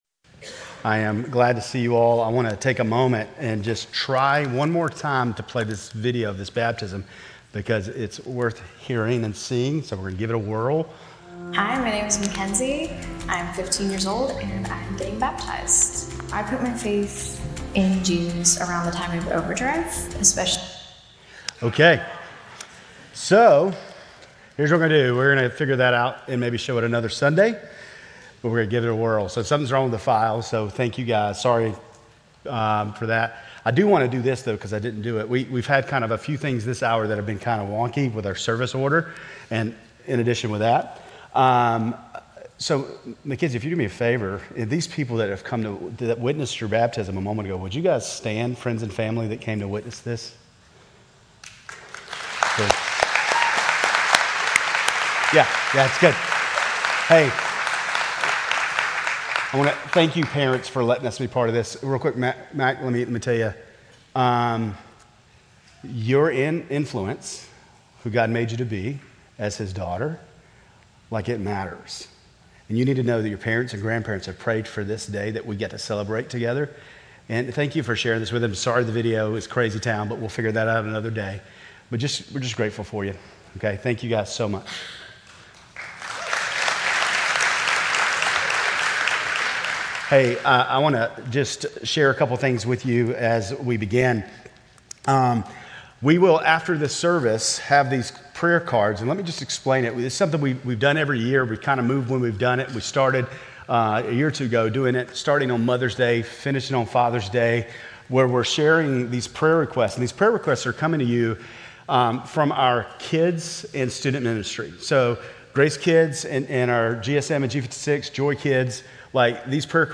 Grace Community Church Lindale Campus Sermons 5_11 Lindale Campus May 11 2025 | 00:36:20 1x 00:00 / 00:36:20 Subscribe Share RSS Feed Share Link Embed